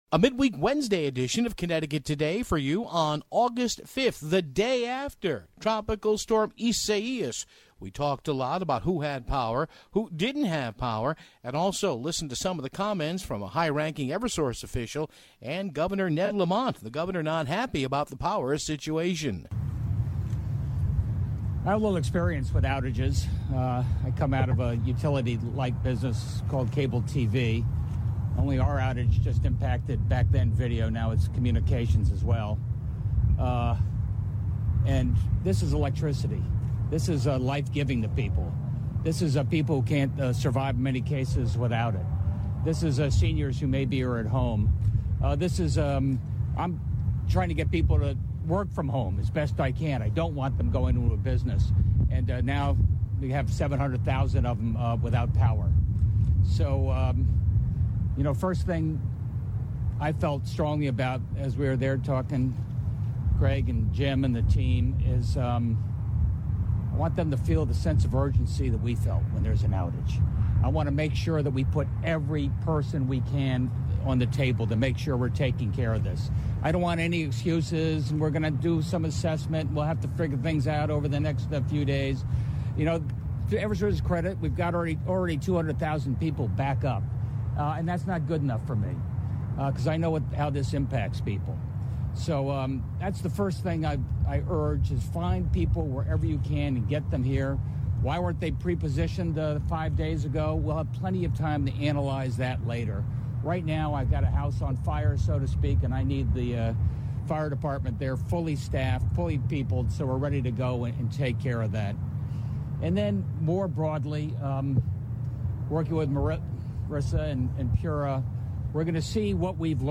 However sprinkled in between some of show's best guests of late was Governor Ned Lamont's Q&A which did show the Governor's frustration over the power situation in the state.